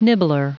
Prononciation du mot nibbler en anglais (fichier audio)
Prononciation du mot : nibbler